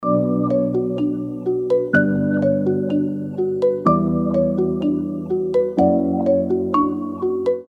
мелодичные
без слов
легкие
биты
Легкая мелодия на что-нибудь